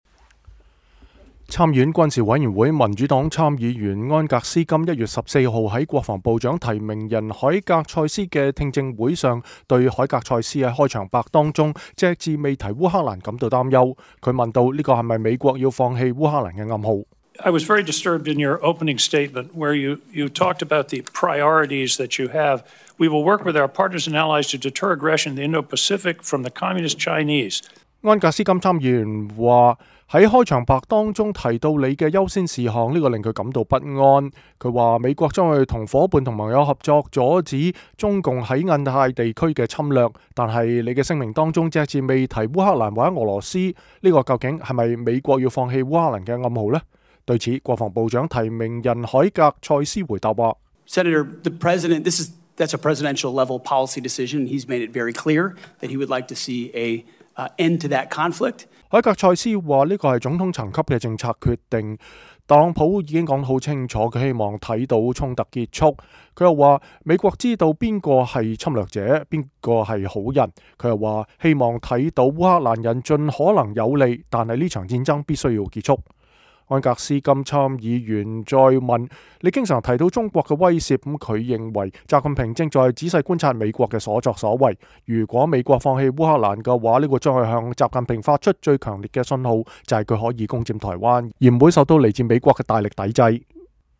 參議院軍事委員會民主黨參議員安格斯·金1月14日在國防部長提名人海格塞斯的確認聽證會上對海格塞斯在開場白中隻字未提烏克蘭感到擔憂。